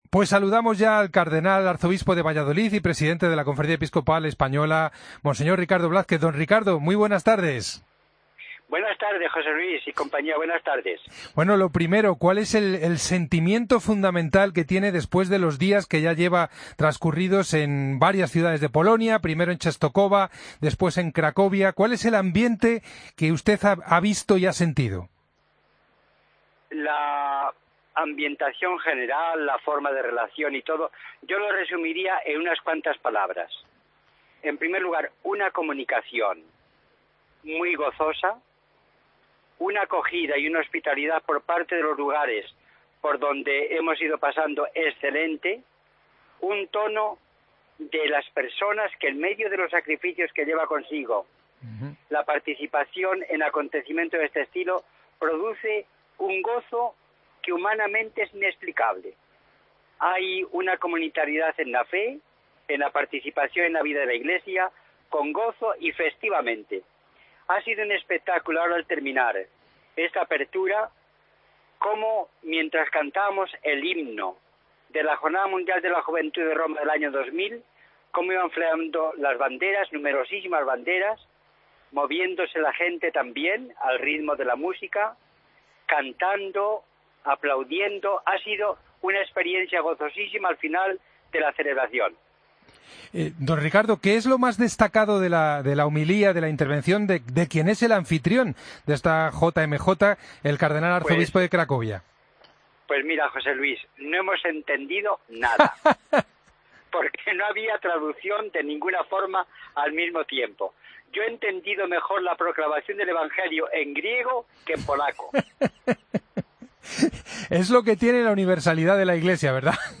Escucha la entrevista al cardenal Ricardo Blázquez en El Espejo, miércoles 27 de julio de 2016
Madrid - Publicado el 27 jul 2016, 15:07 - Actualizado 15 mar 2023, 01:56